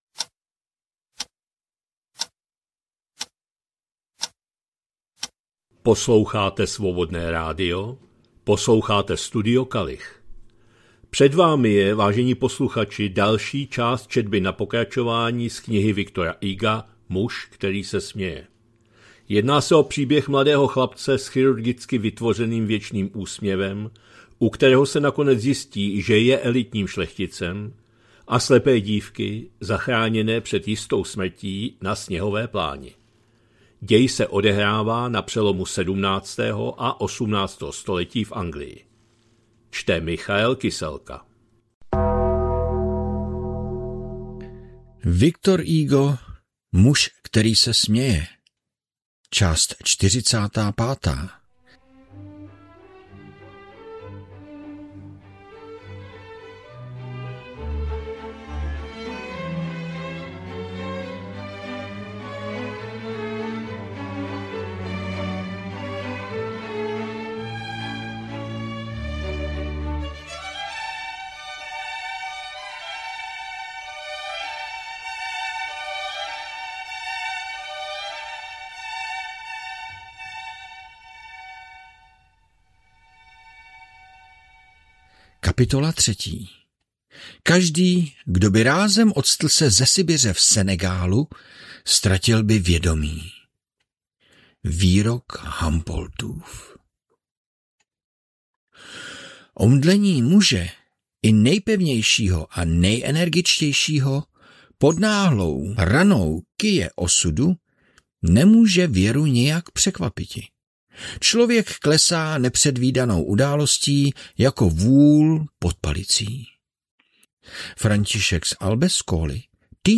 2026-01-21 – Studio Kalich – Muž který se směje, V. Hugo, část 45., četba na pokračování